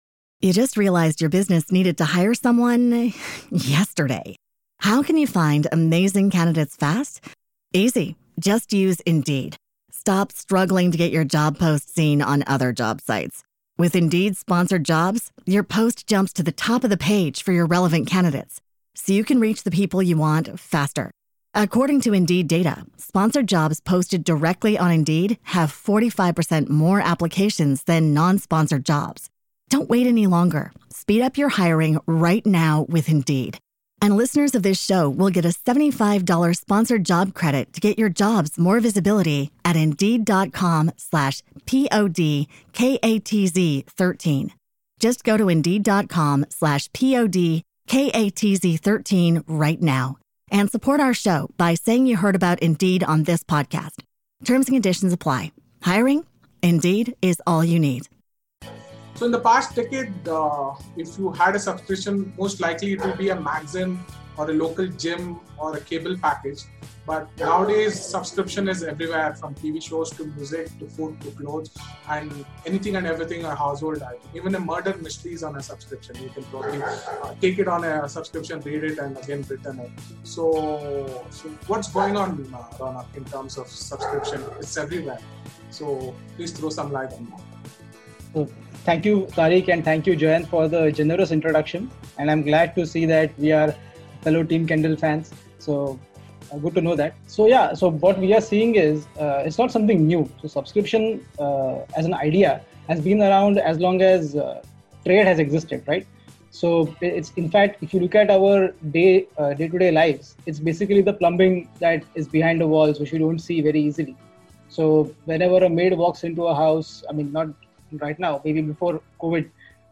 Excerpts from our virtual fireside econ chat